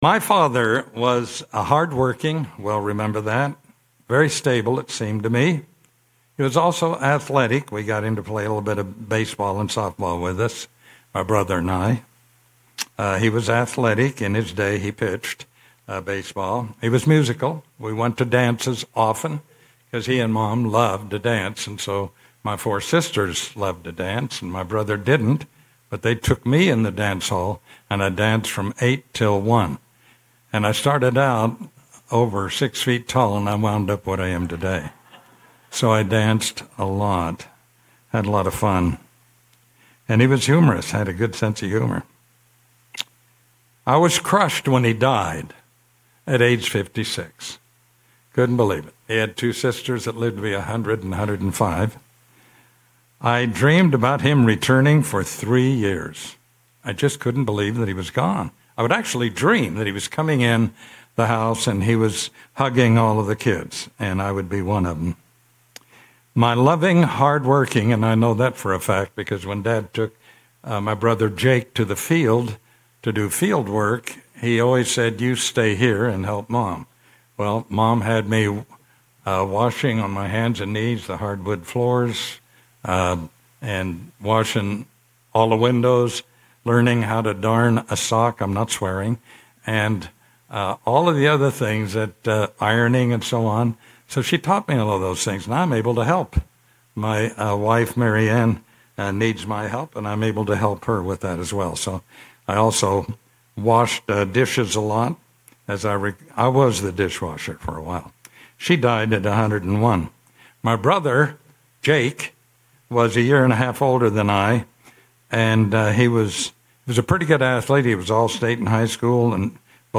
Sermons
Given in Daytona Beach, Florida